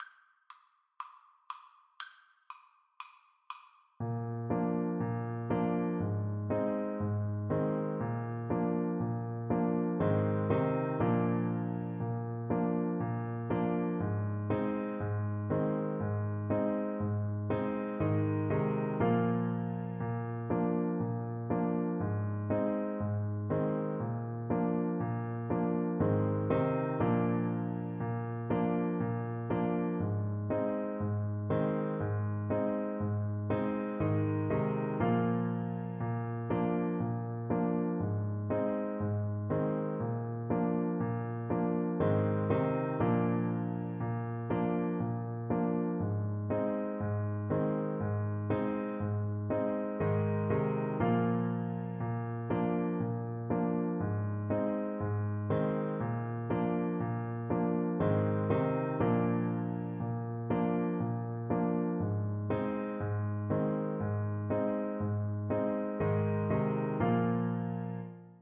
Swing = 120 =120 i = q e
4/4 (View more 4/4 Music)
Pop (View more Pop Clarinet Music)